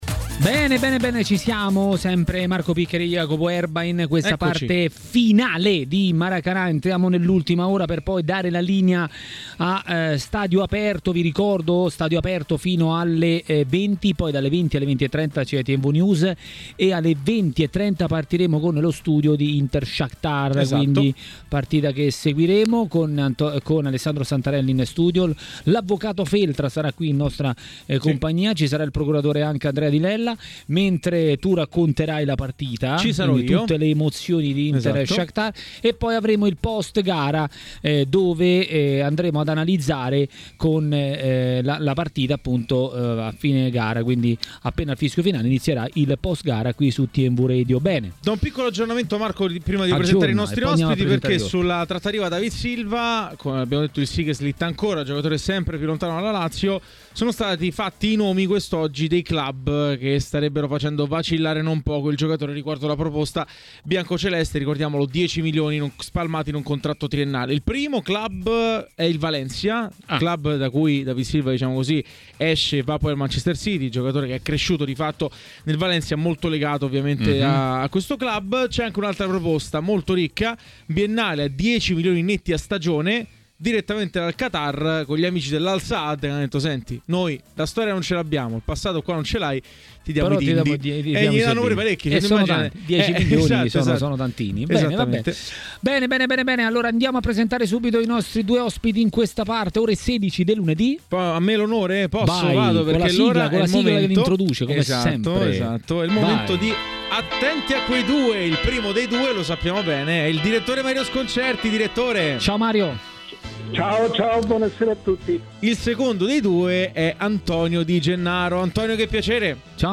A parlare dei temi di giornata a TMW Radio, durante Maracanà, è stato l'ex calciatore e commentatore tv Antonio Di Gennaro.